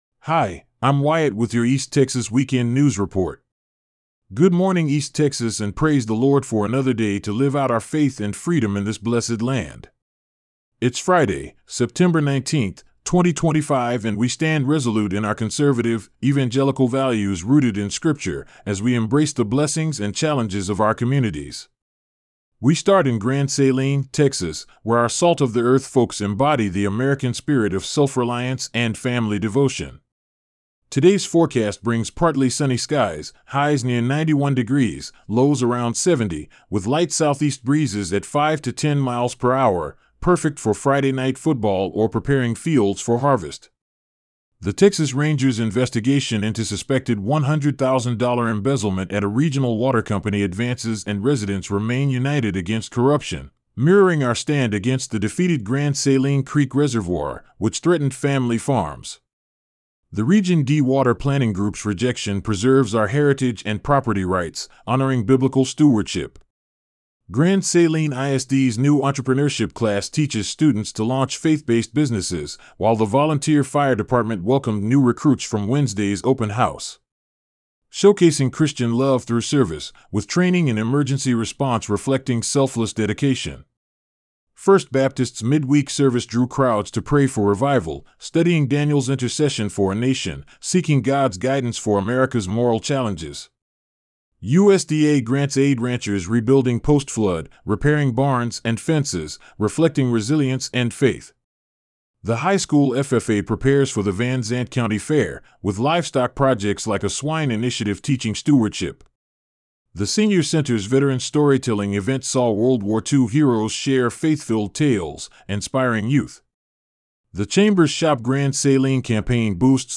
East Texas Weekend News Report for Friday, September 19 - Sunday, September 21, 2025